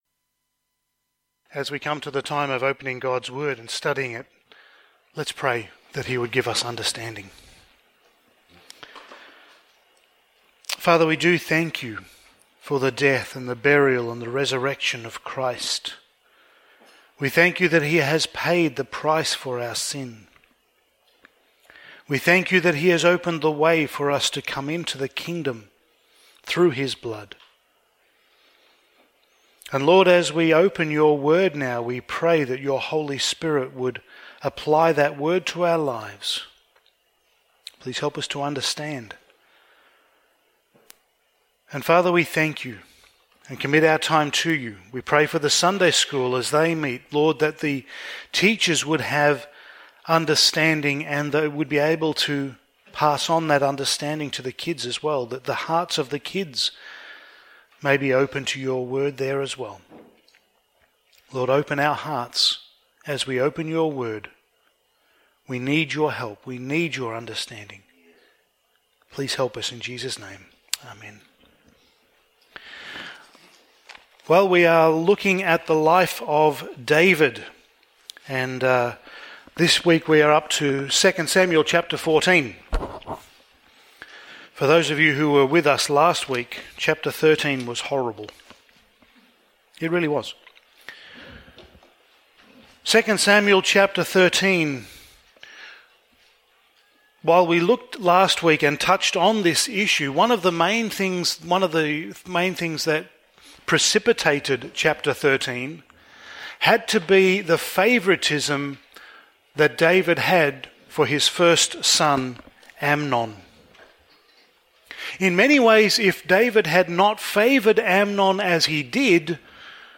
Passage: 2 Samuel 14:1-33 Service Type: Sunday Morning